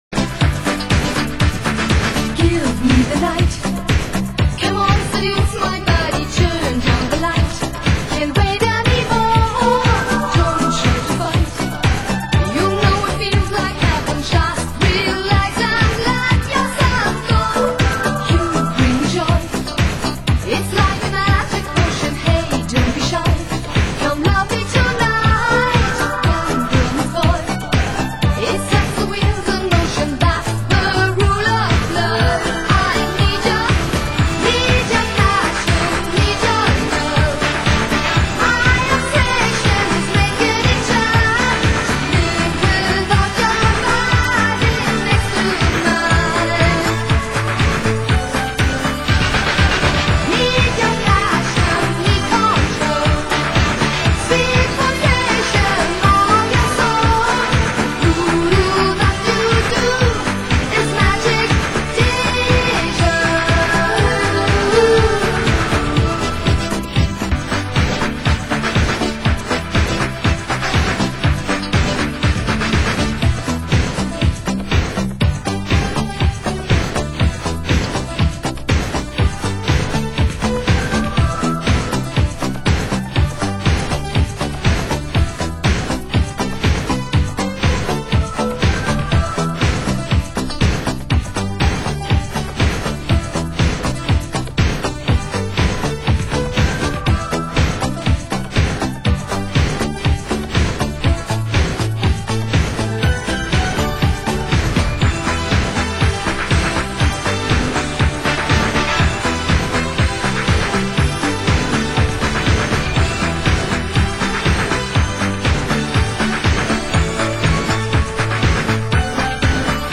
Genre: Italo Disco